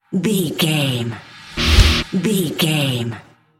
Aeolian/Minor
E♭
drums
electric guitar
bass guitar
hard rock
aggressive
energetic
intense
nu metal
alternative metal